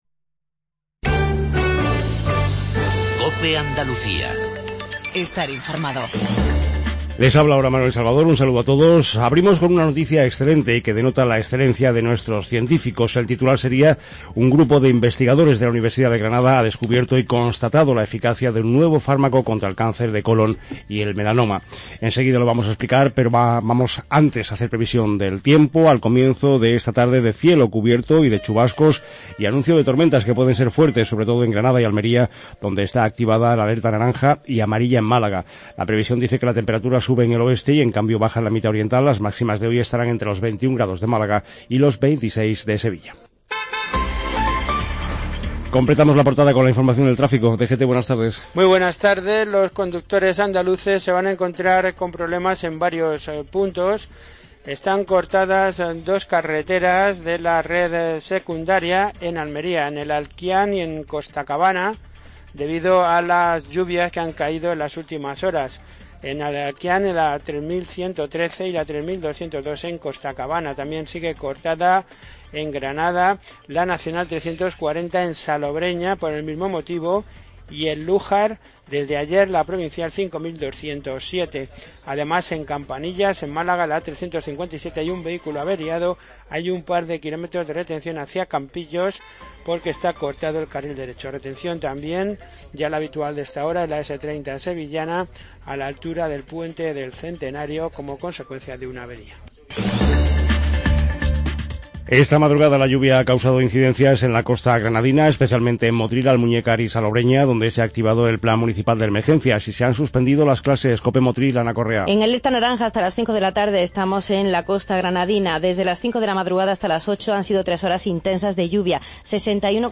INFORMATIVO REGIONAL/LOCAL MEDIODIA